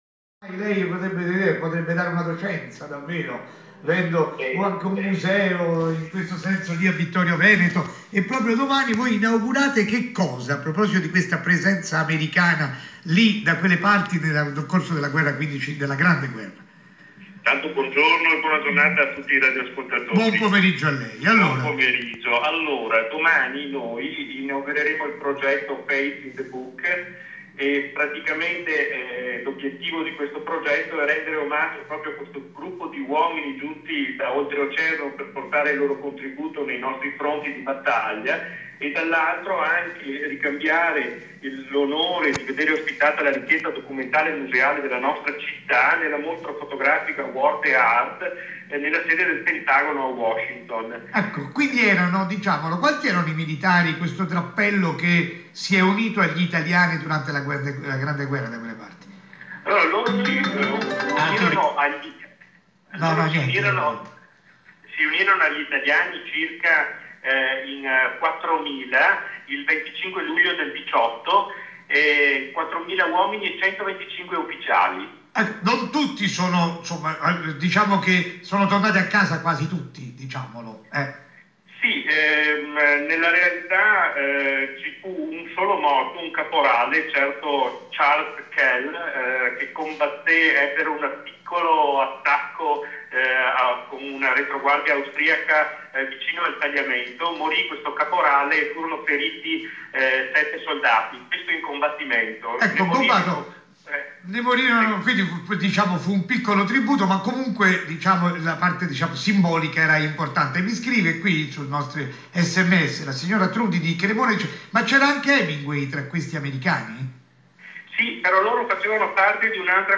Intervista su RAI - ISORADIO del 23 febbraio 2018